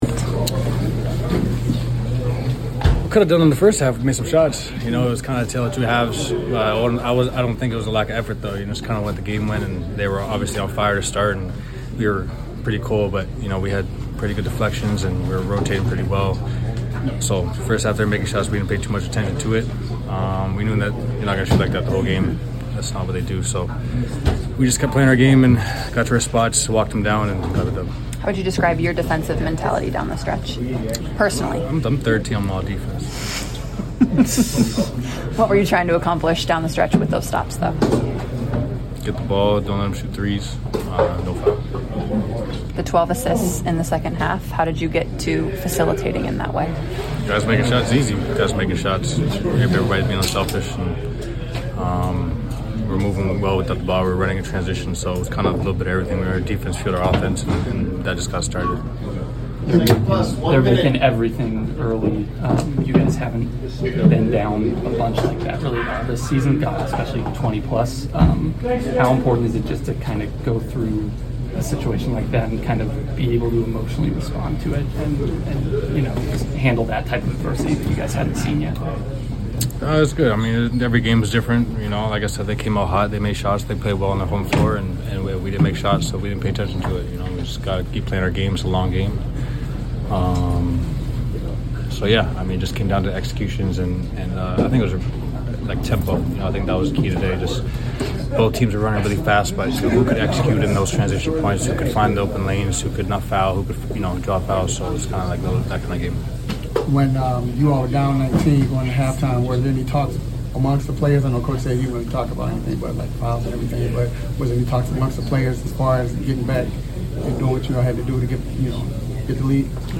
Denver Nuggets Guard Jamal Murray Postgame Interview after defeating the Atlanta Hawks at State Farm Arena.